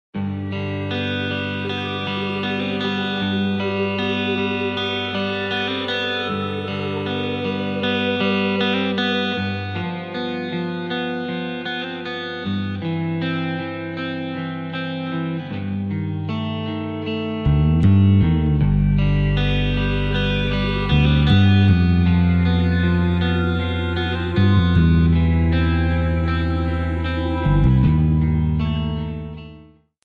Cm
MPEG 1 Layer 3 (Stereo)
Backing track Karaoke
Pop, Rock, 2000s